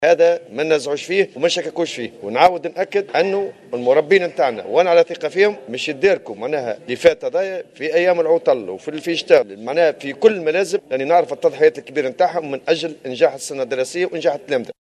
وقال في تصريح على هامش لقاء جمعه أمس برئيس مجلس نواب الشعب، محمد الناصر،: " لدي ثقة بأن المربين سيتداركون ما فات من دروس في العطلة والأيام العطل الرسمية من أجل انجاح السنة الدراسية..".